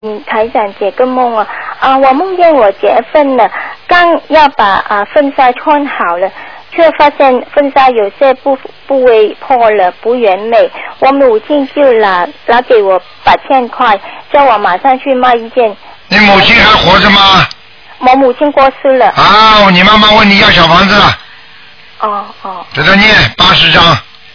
目录：2012年02月_剪辑电台节目录音集锦